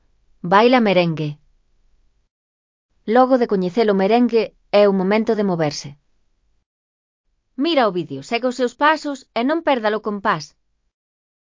Baila merengue
AUD_MUD_6PRI_REA02_BAILA_MERENGUE_V01.mp3